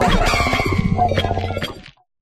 Grito de Ferroverdor.ogg
Grito_de_Ferroverdor.ogg.mp3